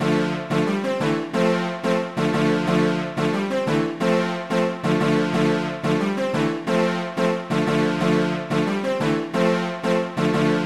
维和小号
描述：中世纪的小号
标签： 90 bpm Cinematic Loops Brass Loops 1.79 MB wav Key : A FL Studio
声道立体声